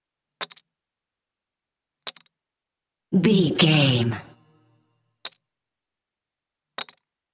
Casino 2 chips table x5
Sound Effects
foley